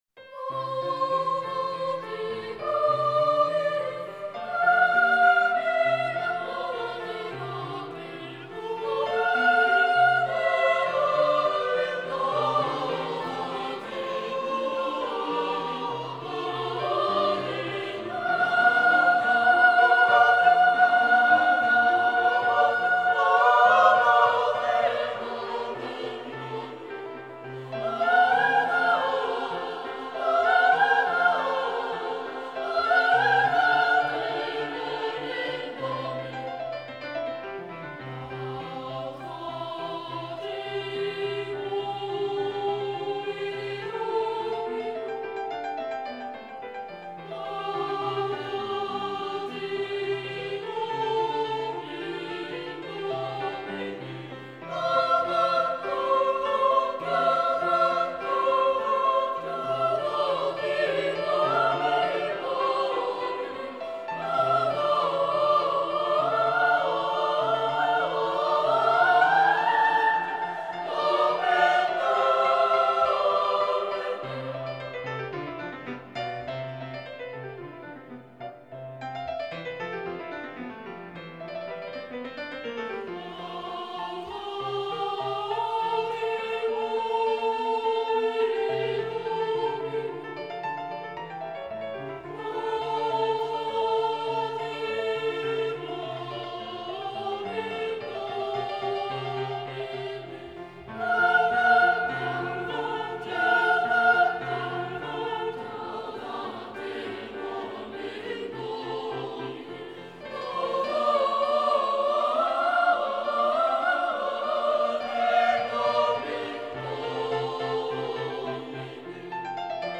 Orgue
Католический хор мальчиков